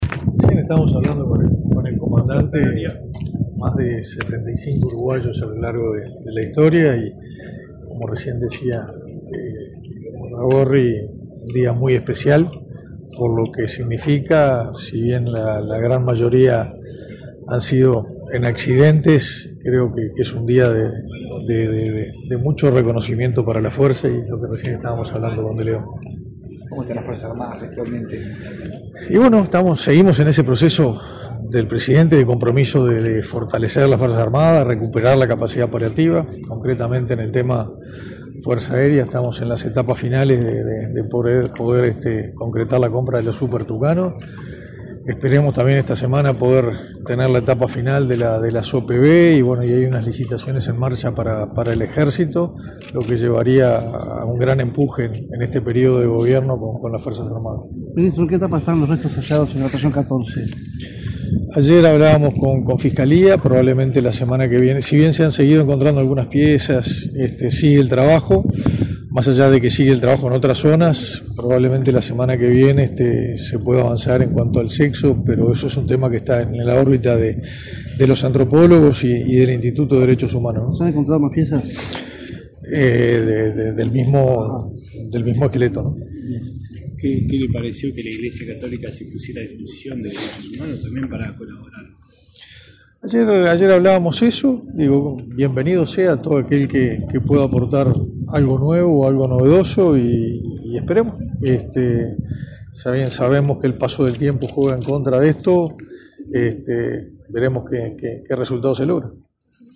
Declaraciones del ministro de Defensa Nacional, Armando Castaingdebat
Declaraciones del ministro de Defensa Nacional, Armando Castaingdebat 10/08/2024 Compartir Facebook X Copiar enlace WhatsApp LinkedIn Este sábado 10, en el Cementerio del Norte de Montevideo, el ministro de Defensa Nacional, Armando Castaingdebat, dialogó con la prensa, luego de participar en el acto conmemorativo del Día de los Mártires de la Aviación Militar.